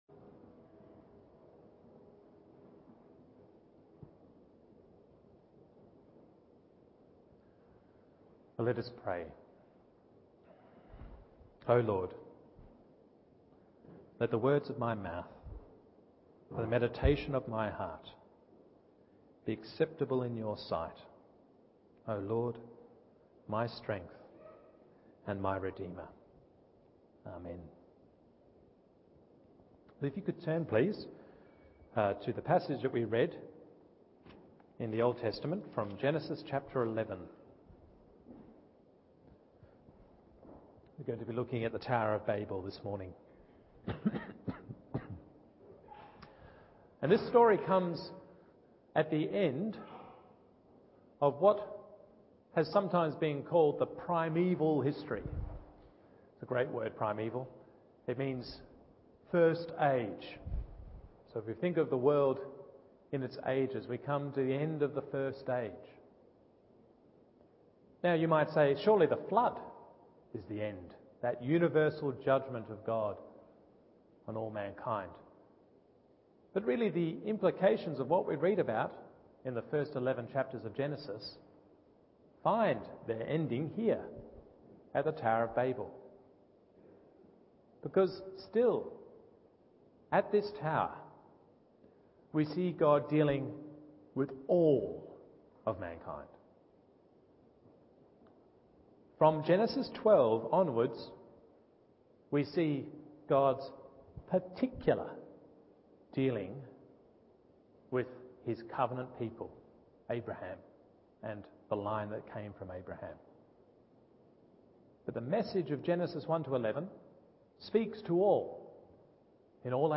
Morning Service Genesis 11:1-9…